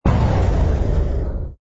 engine_rh_freighter_kill.wav